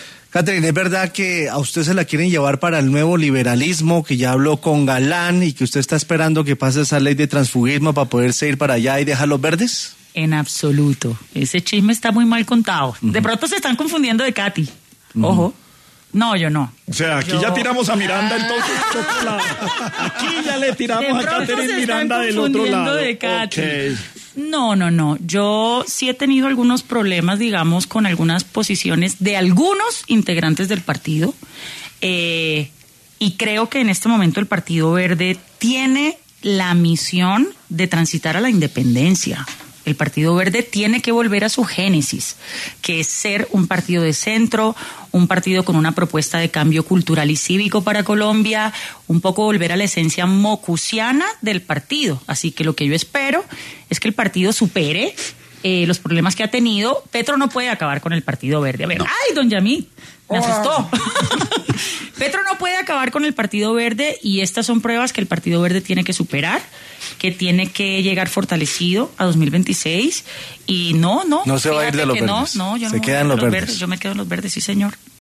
En el programa Sin Anestesia de La Luciérnaga estuvo Catherine Juvinao, Representante a la Cámara, quien habló sobre los rumores que se han hecho sobre su trasladado al partido del Nuevo Liberalismo.